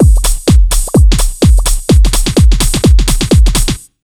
127BEAT6 2-L.wav